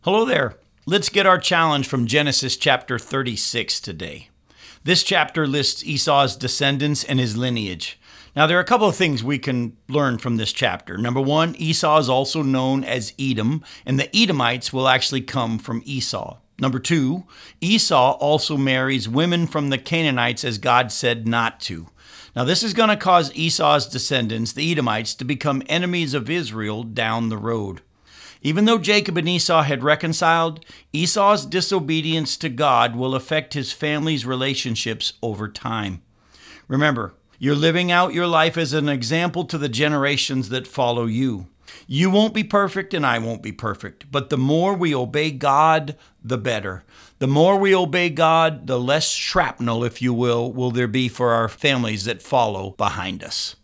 five-minute weekday radio program aired on WCIF 106.3 FM in Melbourne, Florida